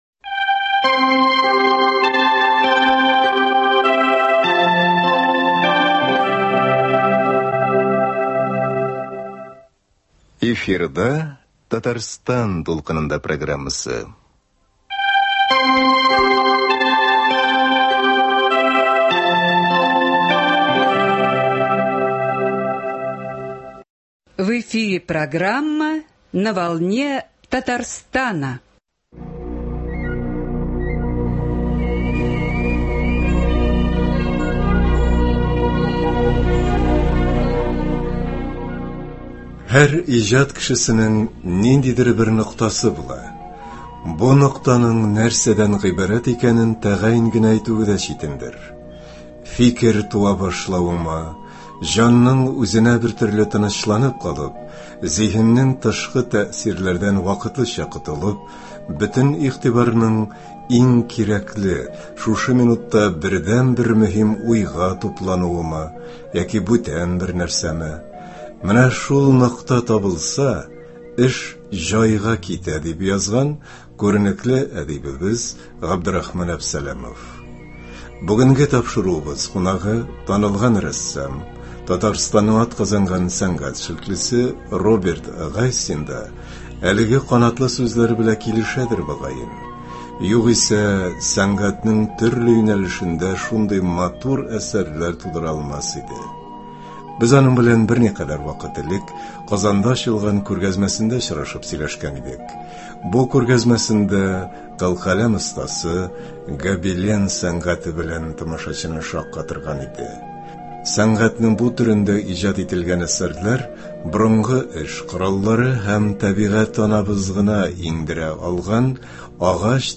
Аның белән гобелен сәнгате турында кызыклы әңгәмә ишетербез.